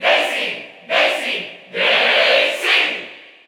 Category: Crowd cheers (SSBU) You cannot overwrite this file.
Daisy_Cheer_Spanish_SSBU.ogg.mp3